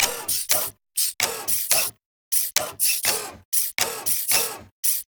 Sfx_creature_chelicerate_seatruckattack_loop_layer_joystick_01.ogg